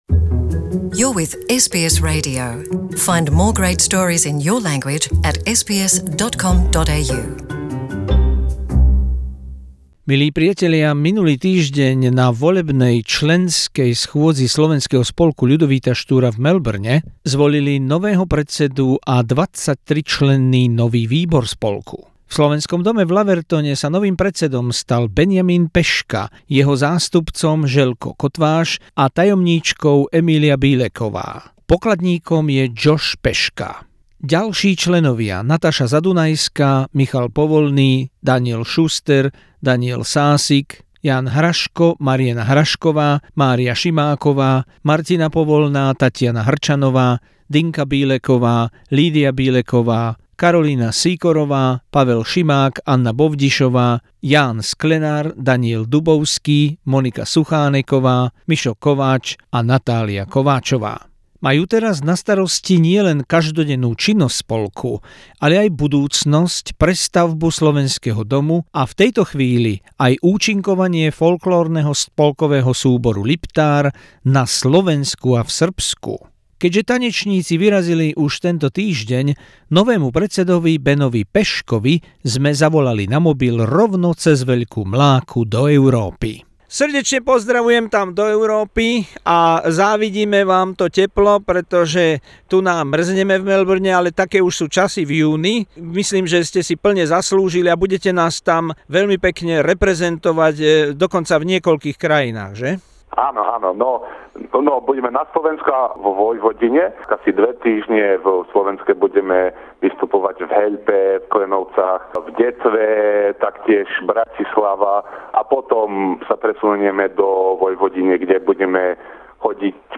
Pár dní po zvolení v Európe. Rozhovor